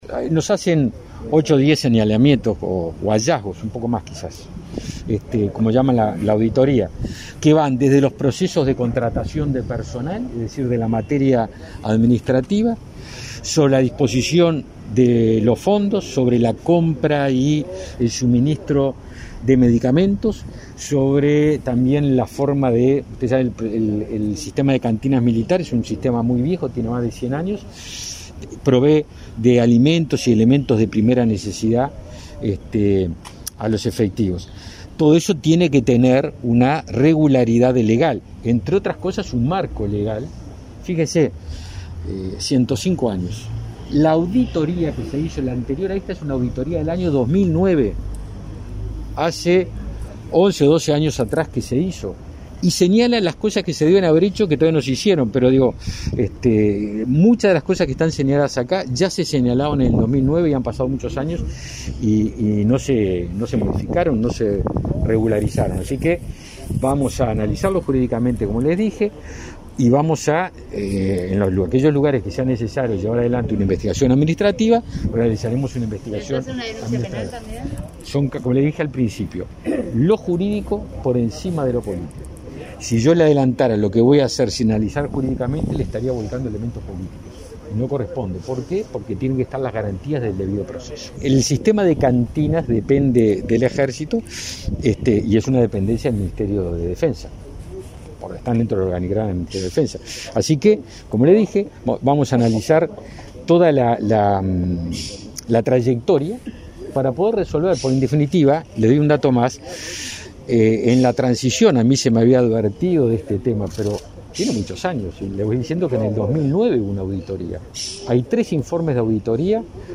El ministro de Defensa Nacional, Javier García se refirió a la auditoría en los servicios de cantinas del Comando General del Ejército, donde surgieron 15 hallazgos, de los que cinco fueron catalogados como riesgo «extremo». García dijo en rueda de prensa que en la transición se le había advertido del tema” y afirmó “me parece que llegó el momento de ordenar la casa y ordenar las cosas”.